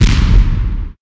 explode1.mp3